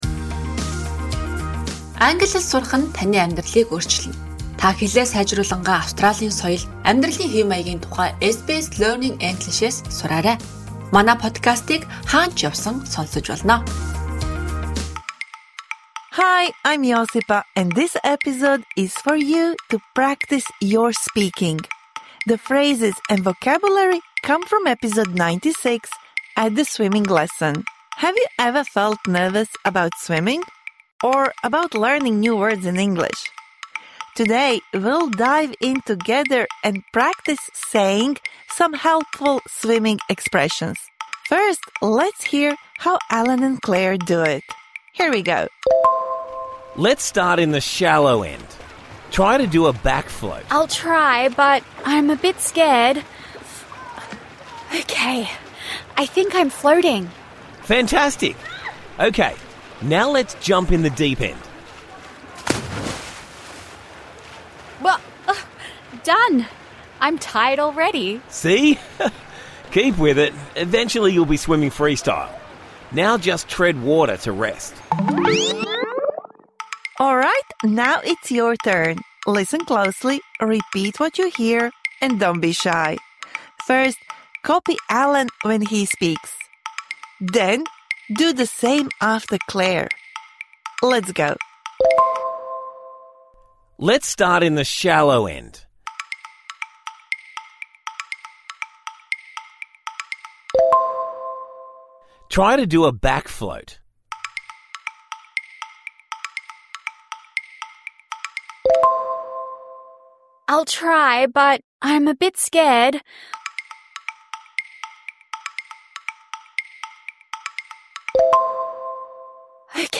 This bonus episode provides interactive speaking practice for the words and phrases you learnt in #96 At a swimming lesson (Med).